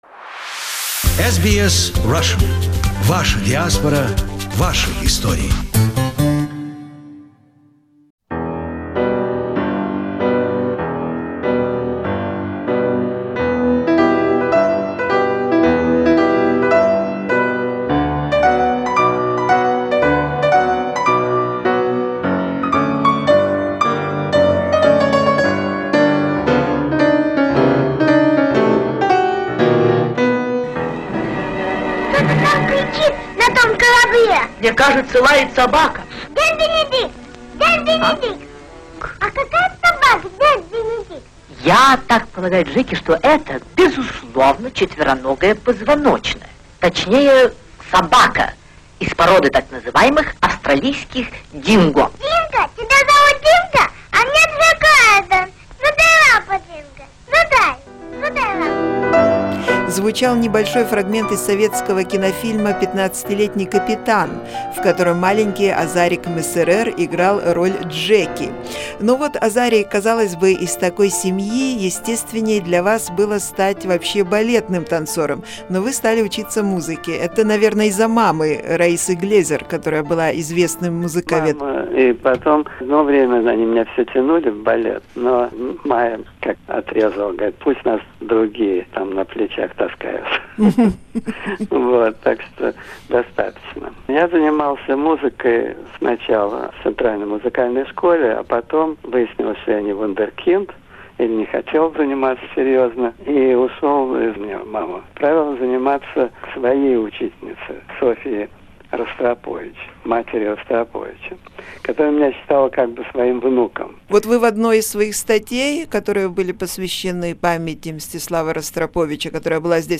The program is illustrated by piano music